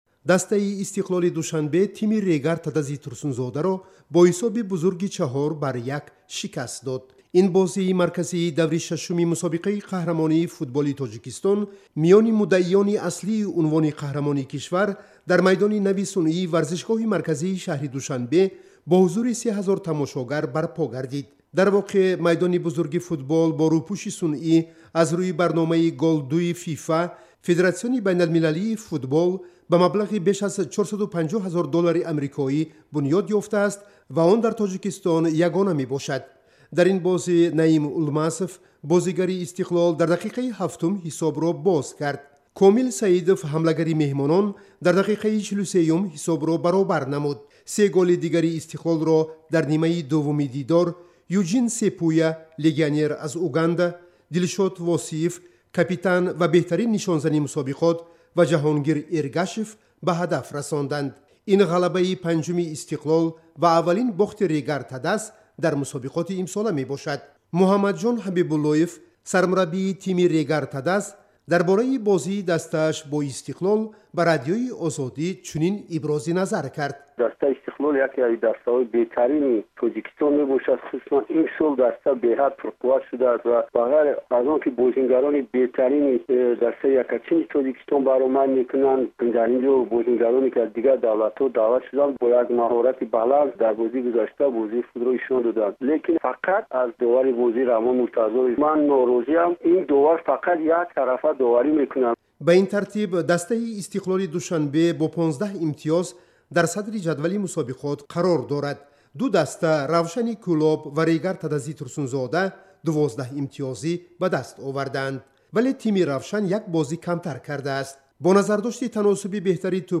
Embed ба дигарон фиристед Гузориш аз бозии"Истиқлол" бо "Регар-ТадАЗ" Аз ҷониби Радиои Озодӣ Embed ба дигарон фиристед Нусхабардорӣ шуд URL нусхабардорӣ шуд Ирсол ба Facebook Ирсол ба Twitter Феълан кор намекунад 0:00 0:02:09 0:00